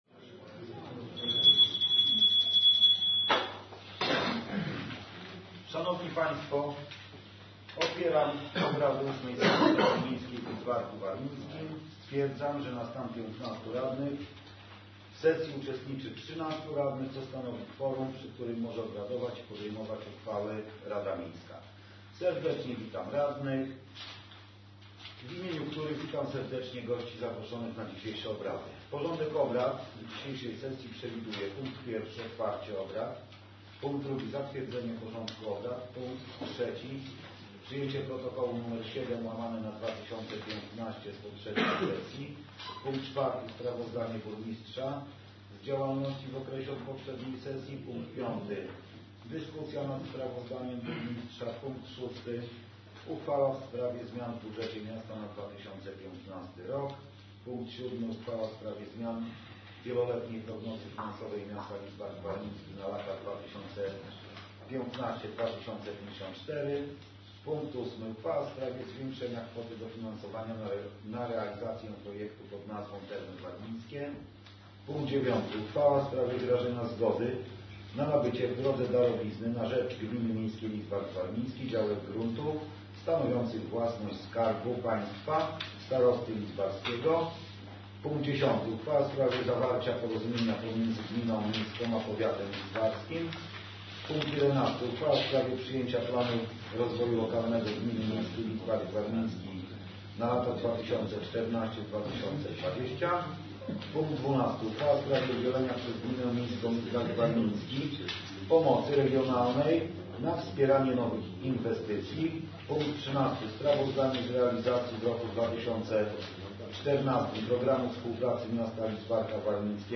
„PO się sypie” – radny Marek Grzmiączka VIII Sesja Rady Miasta
Podczas dyskusji nad sprawozdaniem z pracy Burmistrza, głos zabierali wyłącznie radni z ugrupowania WNMO (związanego z PO).